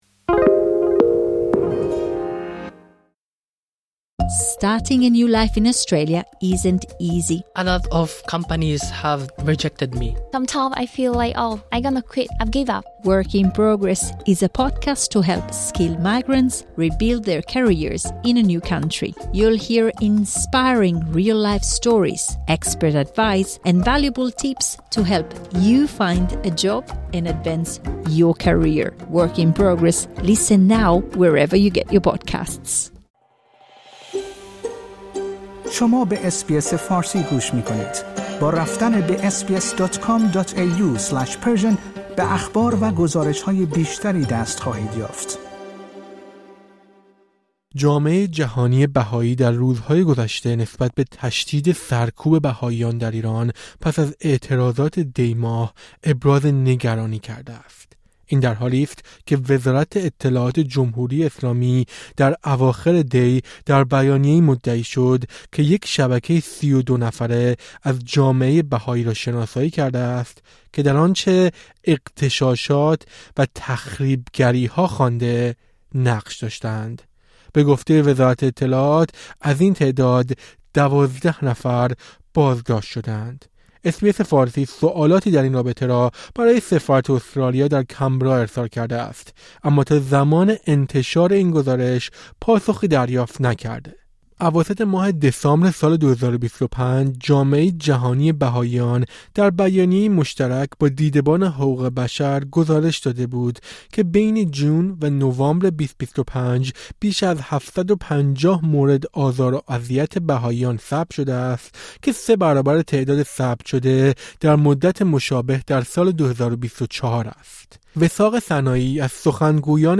گفت‌وگویی